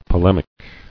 [po·lem·ic]